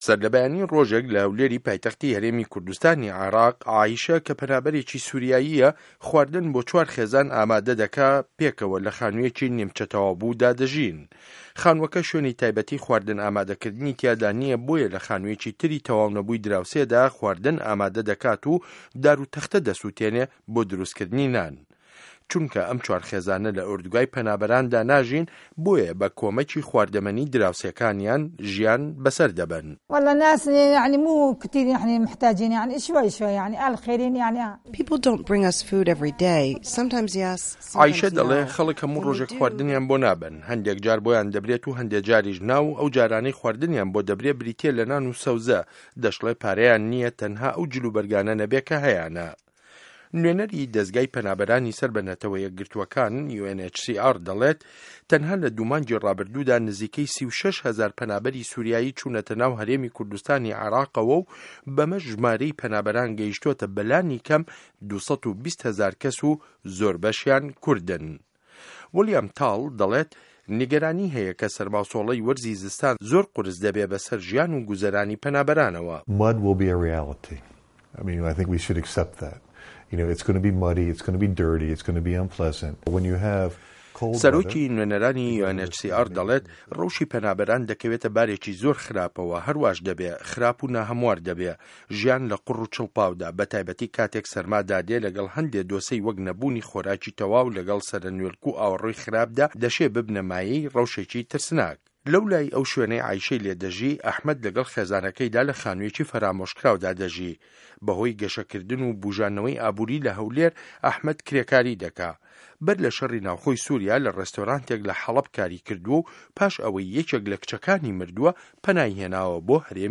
ڕاپۆرتی ڕه‌وشی په‌نابه‌ران له‌ هه‌ولێر